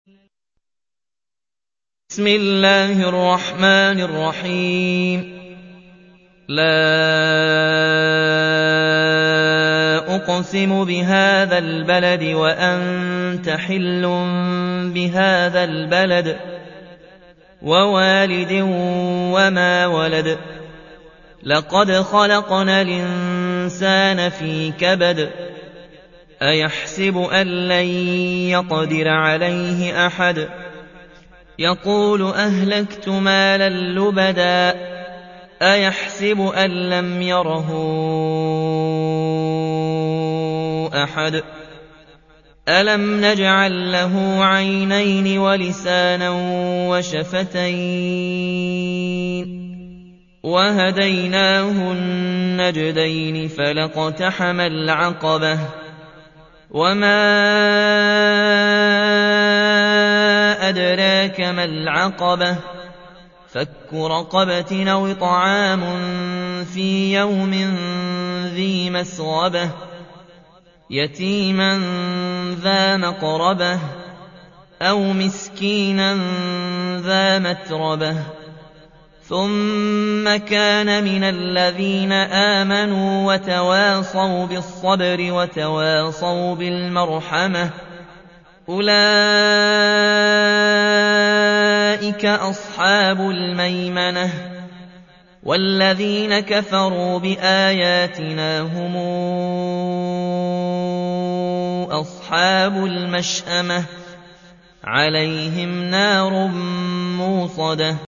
تحميل : 90. سورة البلد / القارئ ياسين الجزائري / القرآن الكريم / موقع يا حسين